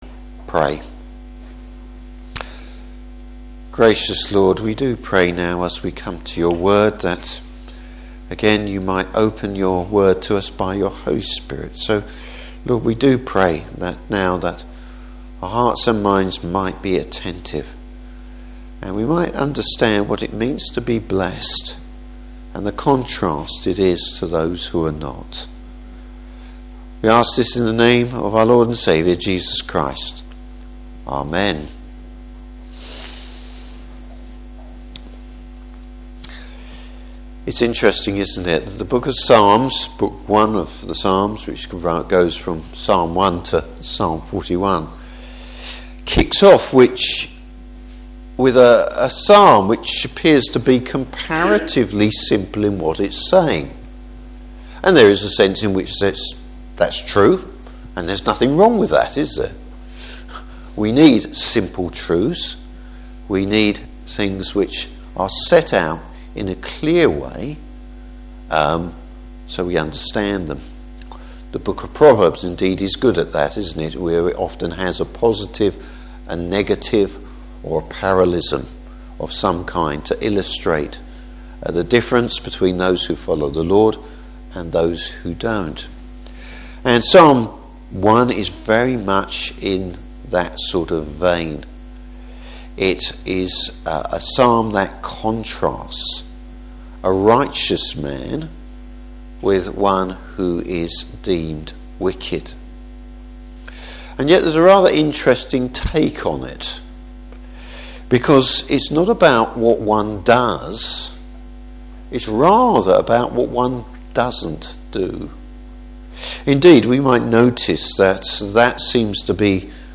Service Type: Evening Service What we don’t do which pleases God.